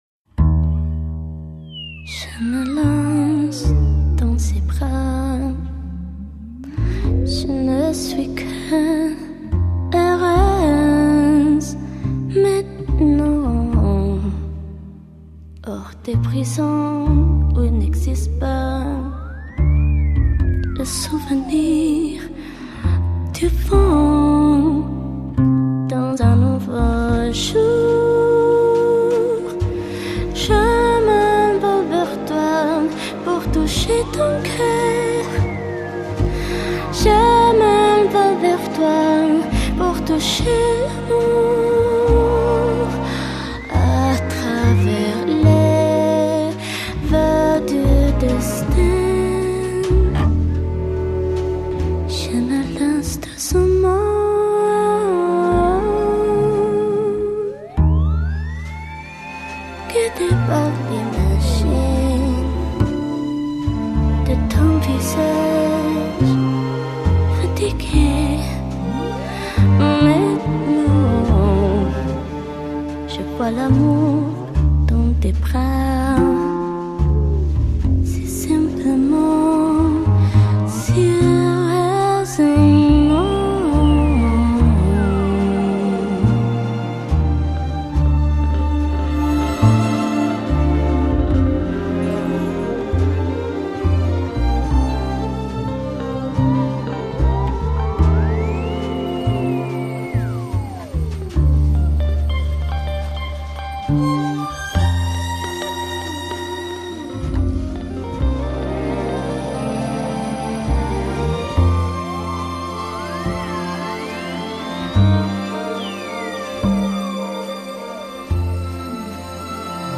Une petite voix hésitante sur du français